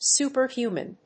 発音記号
• / ˌsupɝˈhjumʌn(米国英語)